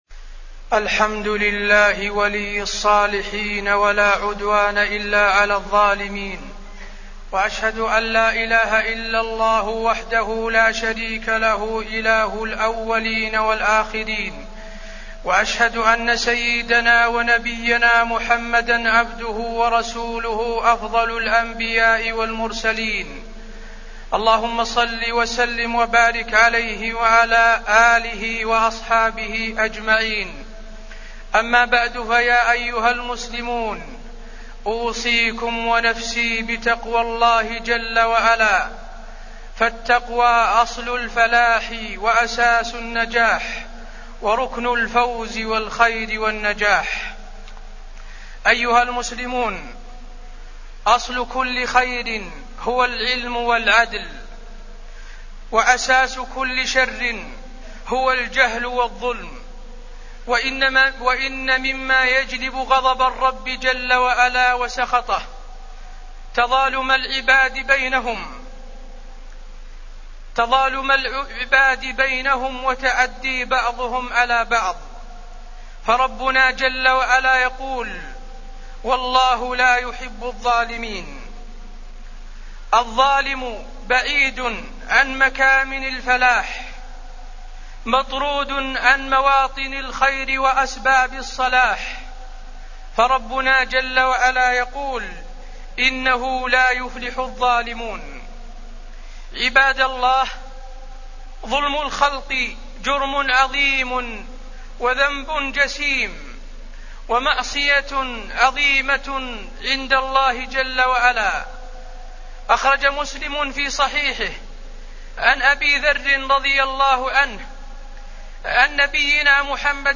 تاريخ النشر ١٦ جمادى الآخرة ١٤٢٩ هـ المكان: المسجد النبوي الشيخ: فضيلة الشيخ د. حسين بن عبدالعزيز آل الشيخ فضيلة الشيخ د. حسين بن عبدالعزيز آل الشيخ الظلم